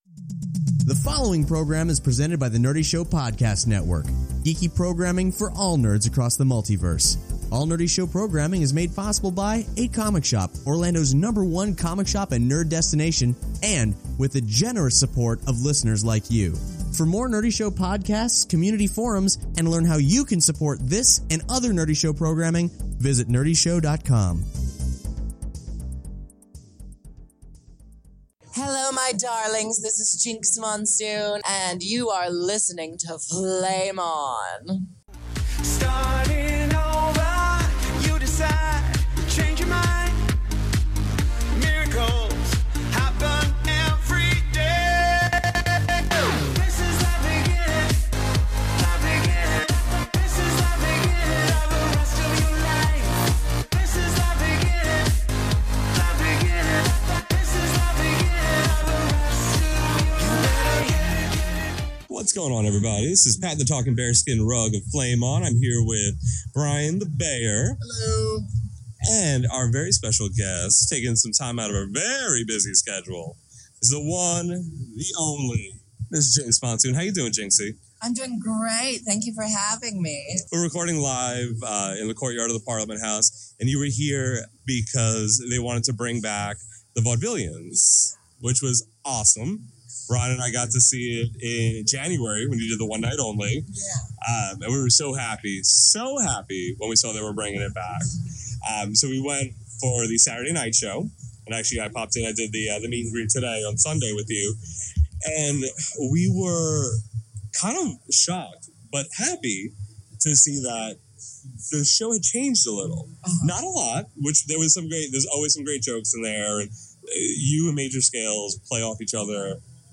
in the courtyard of the Parliament House. From giving us a look into the head and heart of what makes Jinkx tick, her gay and geeky heroes, to whom she thinks will take the CROWN on this season of RuPaul’s Drag Race, Jinkx holds nothing back.